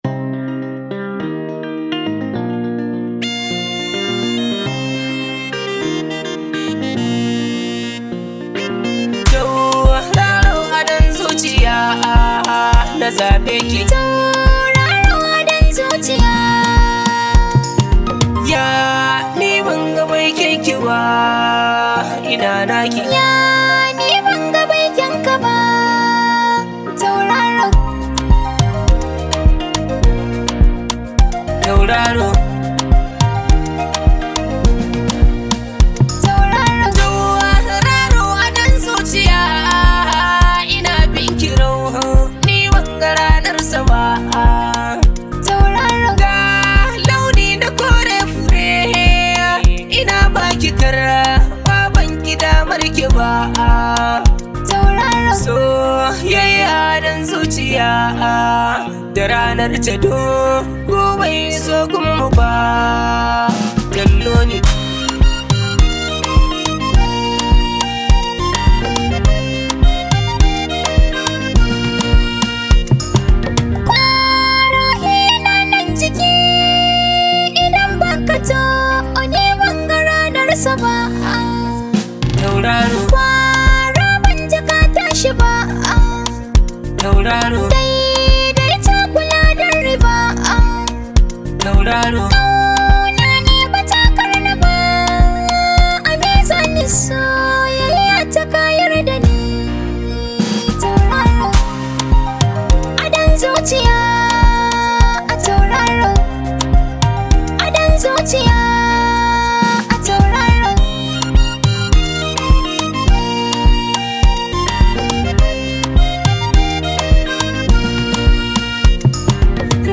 high vibe hausa song